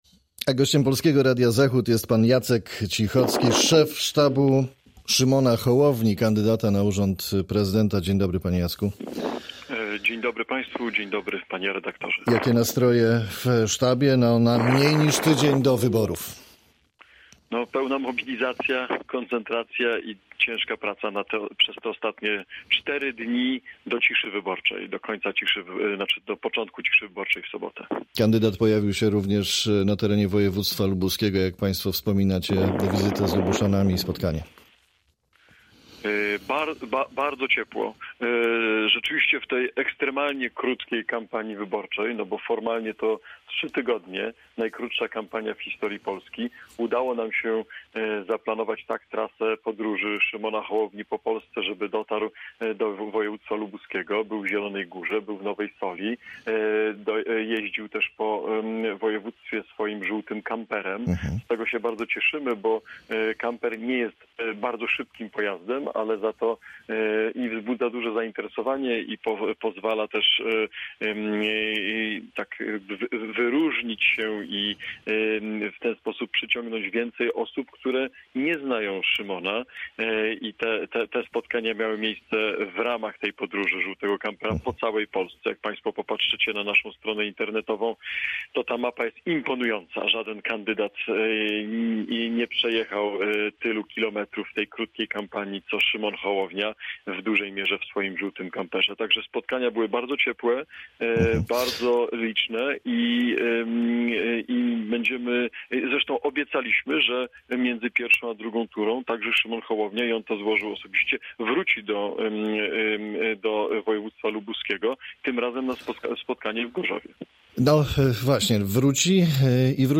Z szefem sztabu Szymona Hołowni, kandydata na prezydenta RP rozmawia